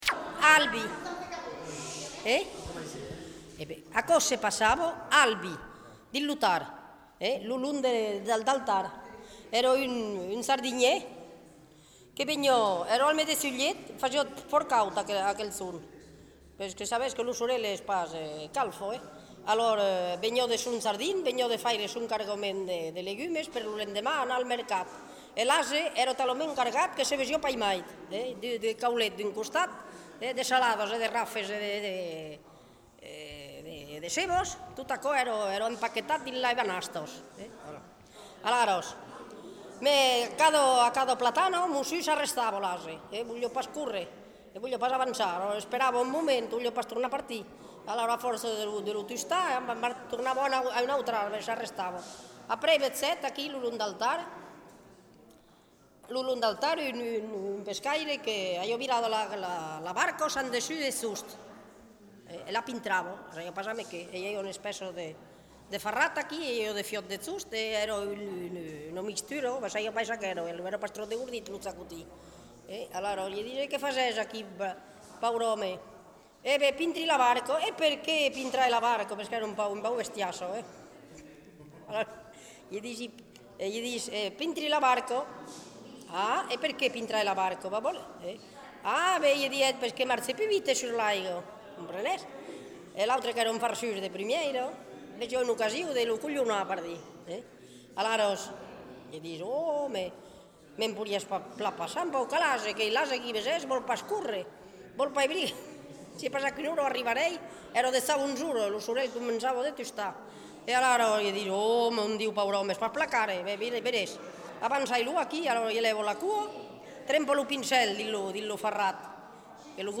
Aire culturelle : Lauragais
Genre : conte-légende-récit
Effectif : 1
Type de voix : voix de femme
Production du son : parlé